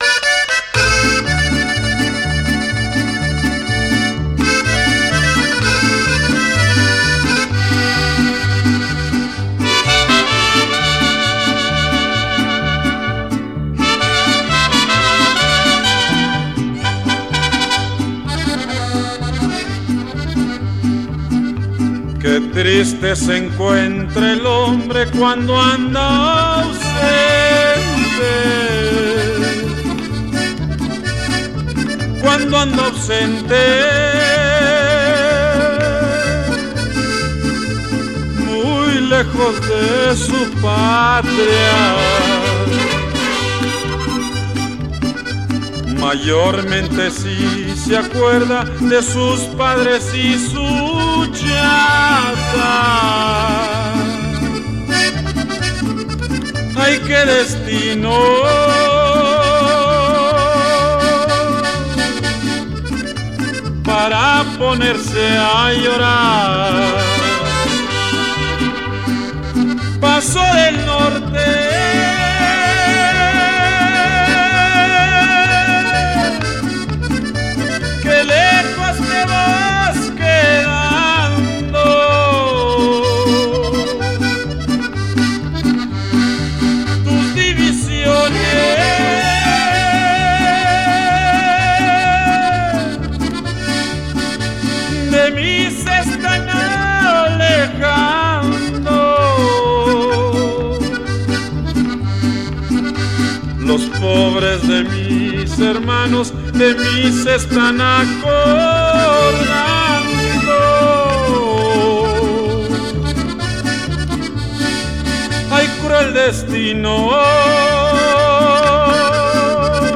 This is a classic of Mexican nortena style